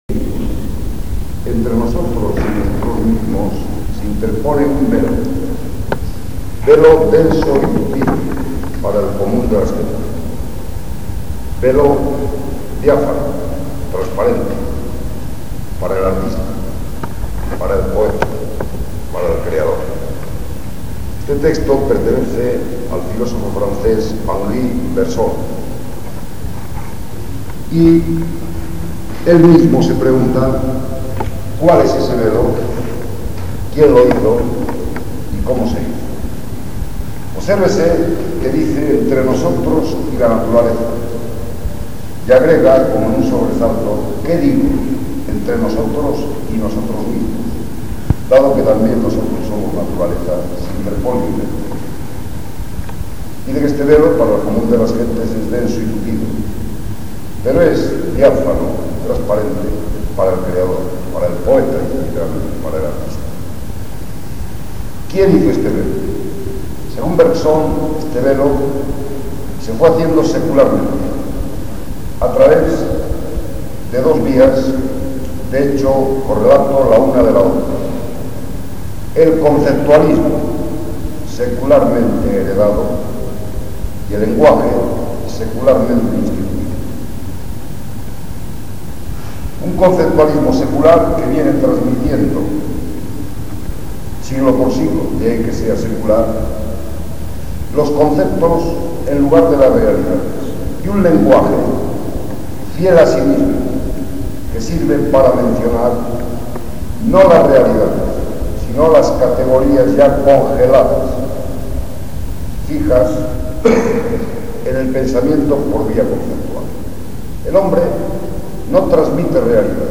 CONFERENCIAS